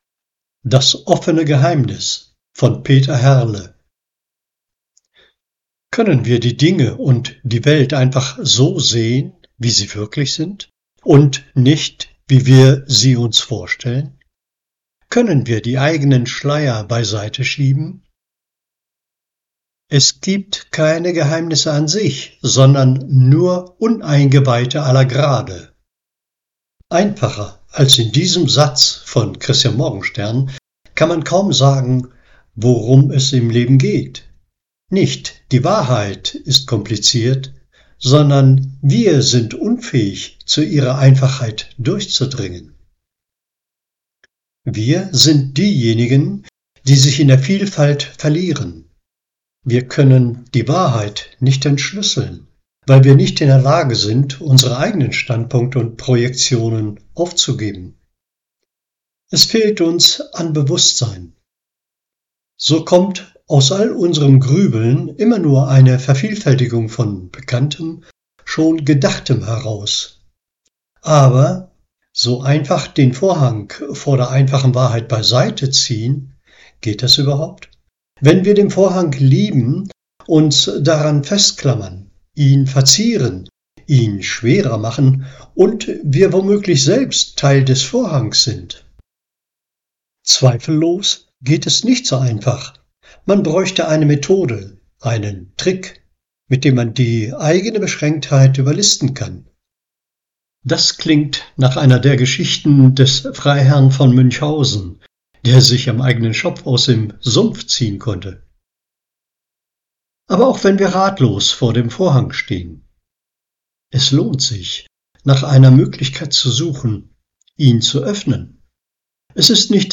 Logon-Artikel gelesen